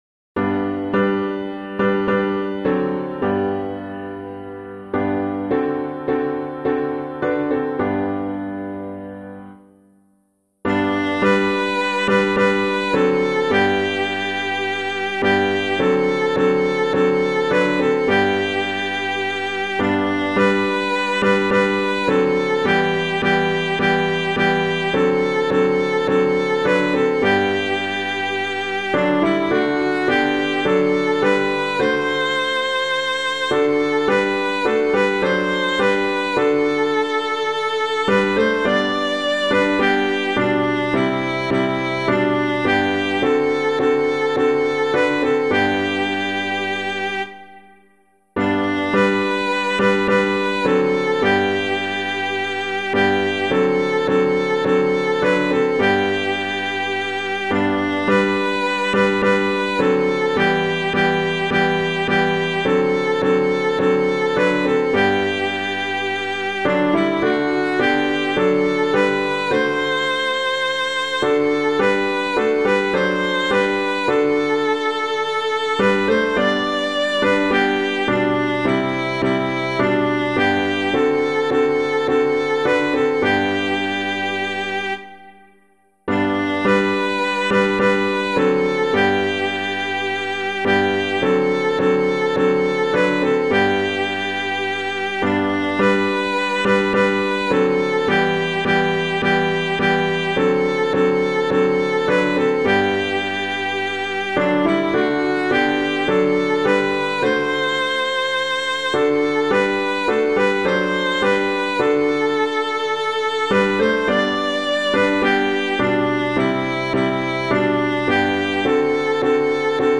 piano
For God Risk Everything [Troeger - BEALOTH] - piano.mp3